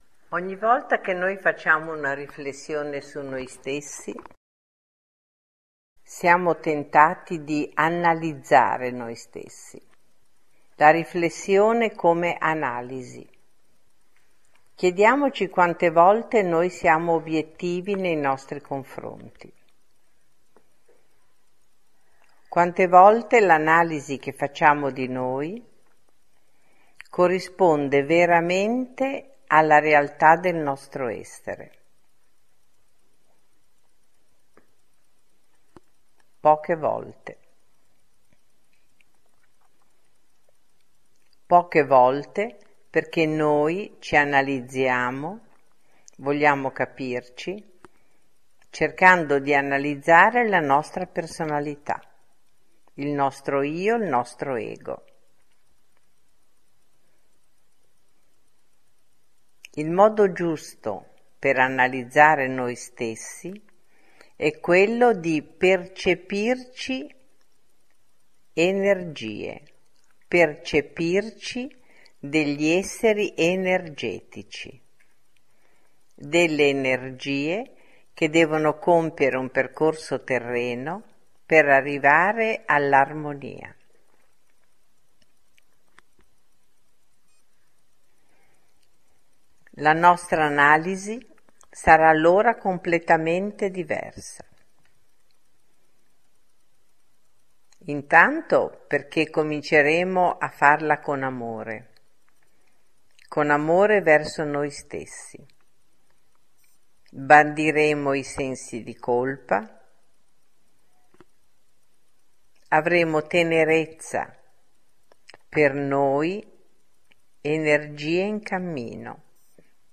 Analizziamoci con Amore – meditazione